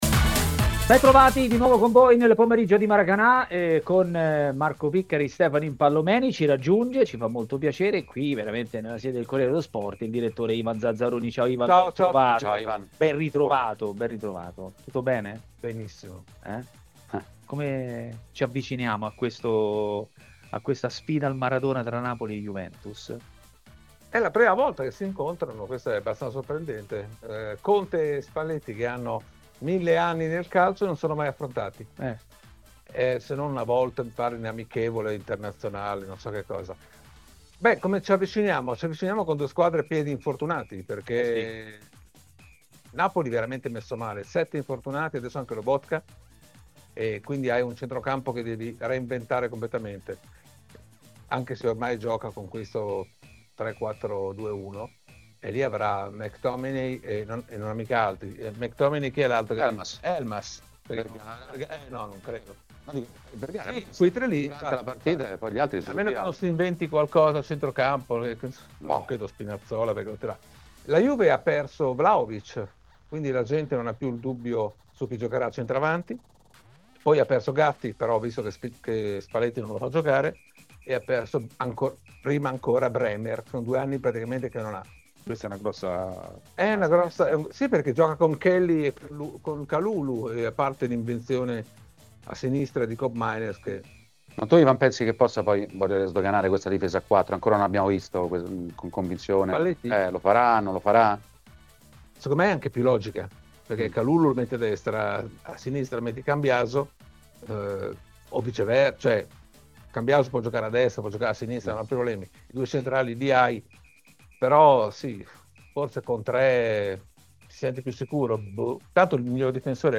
L'ex portiere Michelangelo Rampulla è intervenuto a TMW Radio, durante Maracanà, per parlare dei temi del giorno.